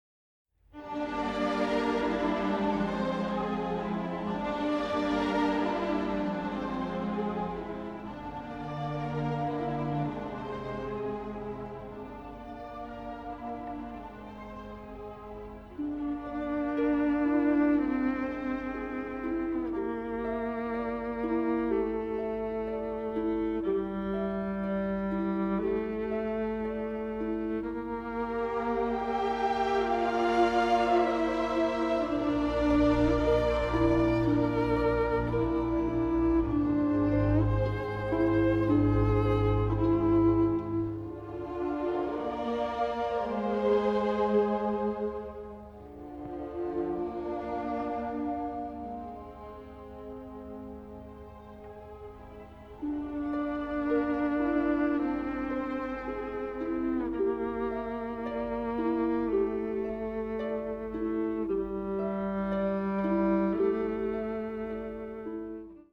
original soundtrack